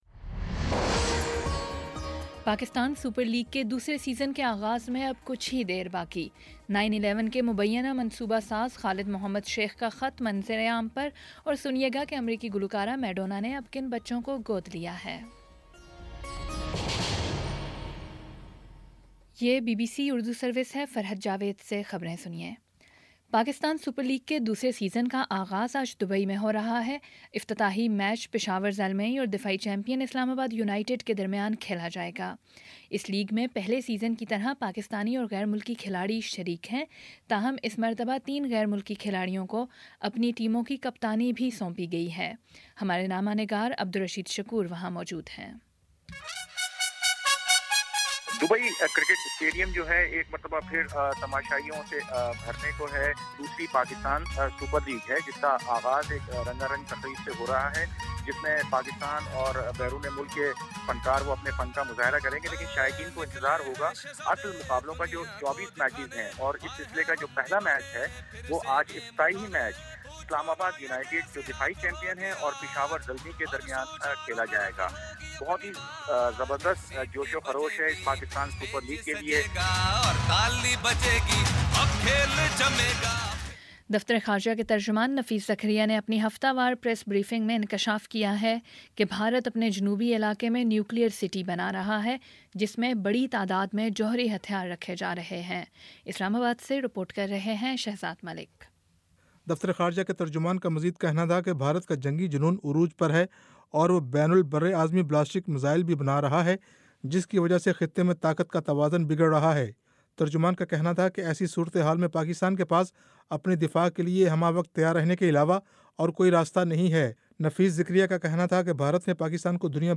فروری 09 : شام سات بجے کا نیوز بُلیٹن